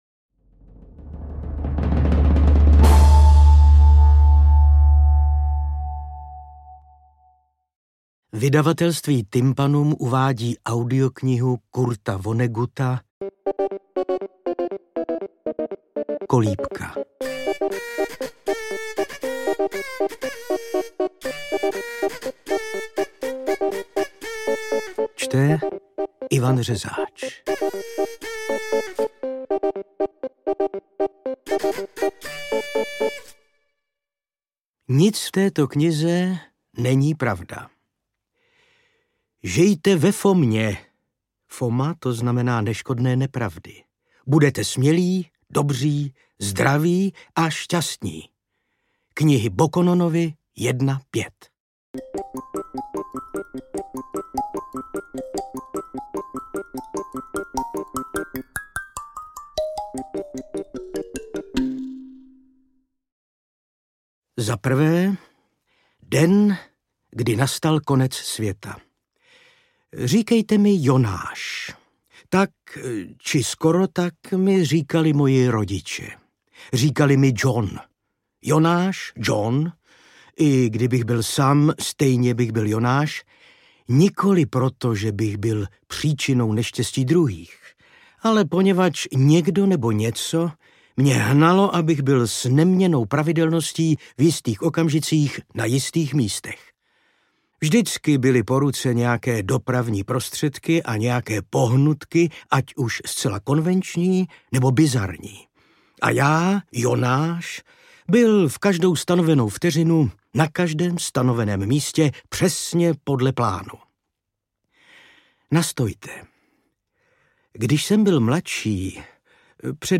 Interpret:  Ivan Řezáč
AudioKniha ke stažení, 21 x mp3, délka 8 hod. 19 min., velikost 456,8 MB, česky